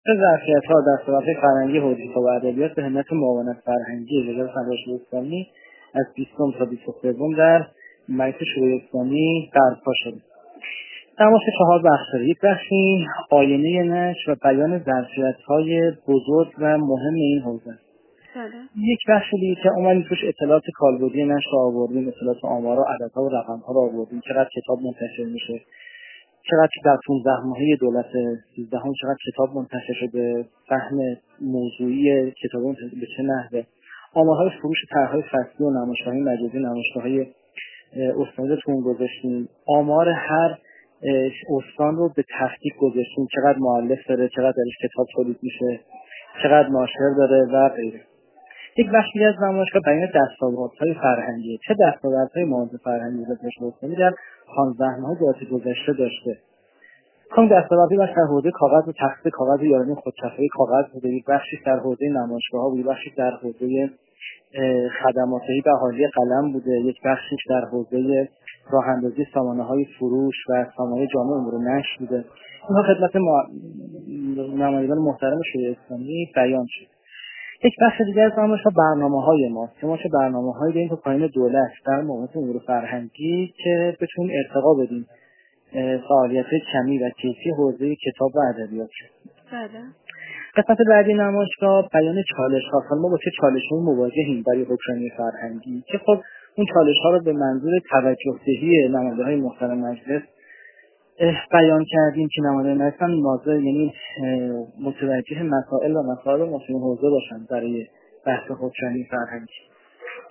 افتتاح نخستین نمایشگاه ظرفیت‌ها و دستاوردهای فرهنگی حوزه کتاب و ادبیات در مجلس شورای اسلامیمحمدعلی مرادیان، مدیرکل دفتر مطالعات و برنامه‌ریزی فرهنگی و کتابخوانی وزارت فرهنگ و ارشاد اسلامی در گفت‌وگو با خبرنگار ایکنا درباره نخستین نمایشگاه ظرفیت‌ها و دستاوردهای فرهنگی حوزه کتاب و ادبیات که از 20 تا 23 آذرماه در مجلس شورای اسلامی برپا شده است گفت: این نمایشگاه چهار بخش دارد.